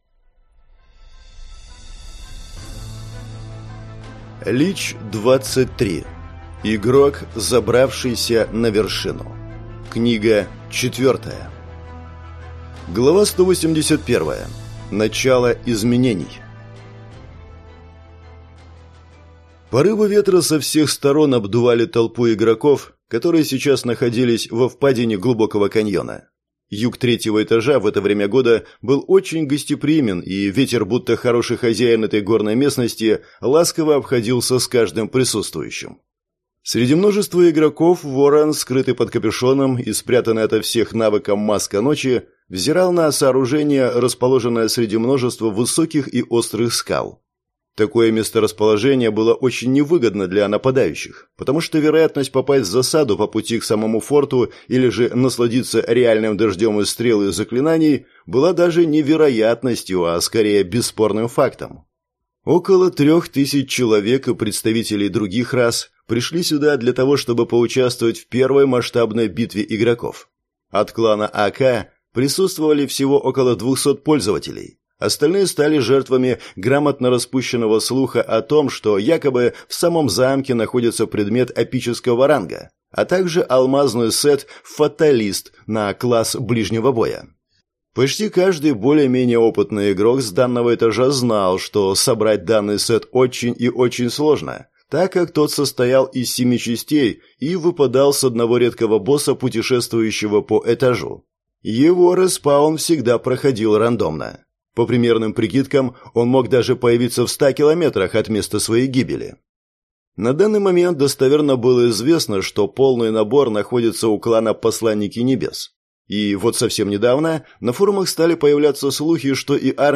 Аудиокнига Игрок, забравшийся на вершину. Книга 4 | Библиотека аудиокниг